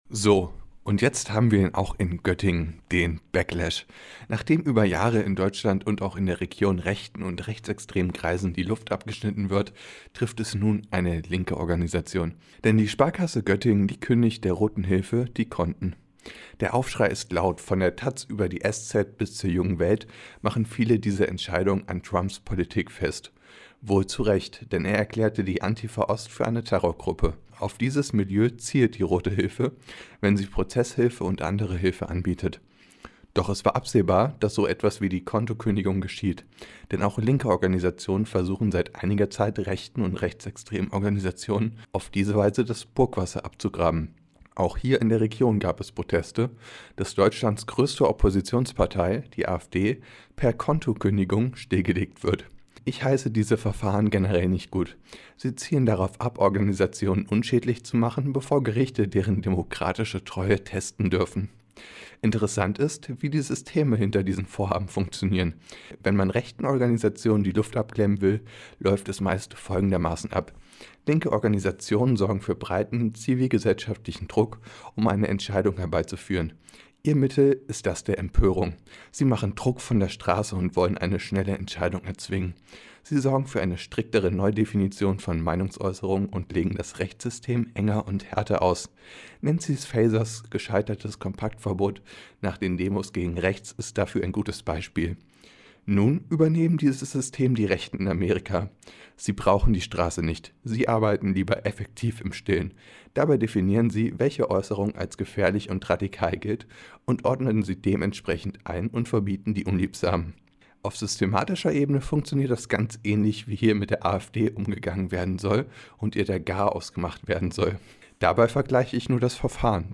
Sendung: Mittendrin Redaktion Kommentar